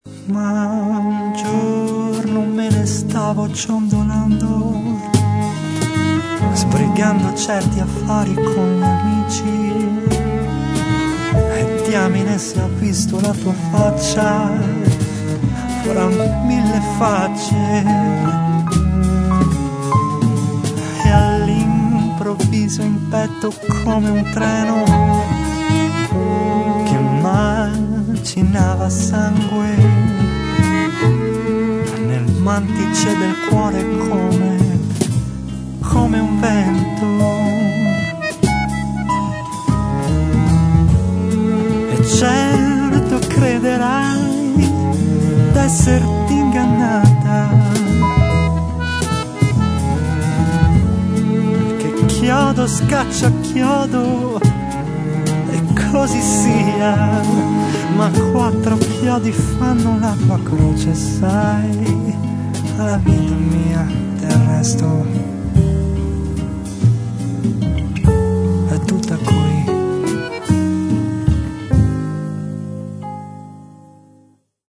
registrato e missato alla
chitarre acustiche e classiche